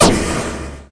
laser_start.ogg